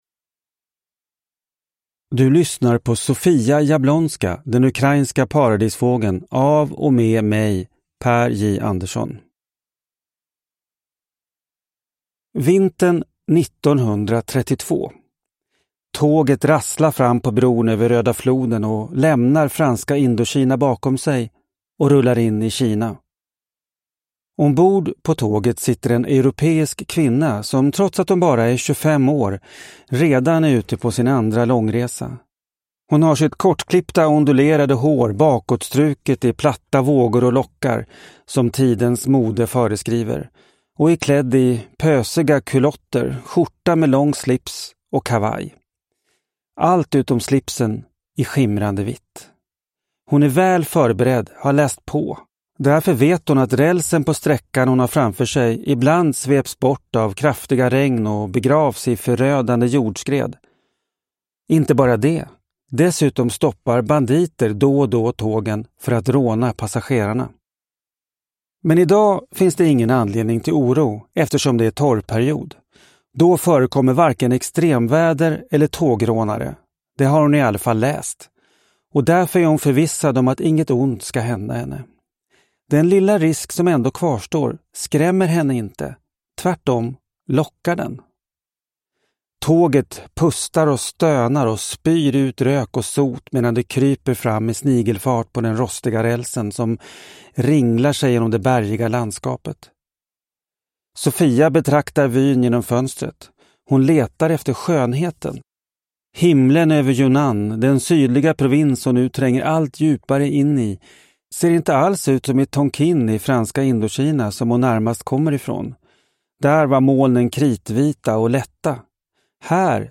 Sofia Jablonska – Den ukrainska paradisfågeln – Ljudbok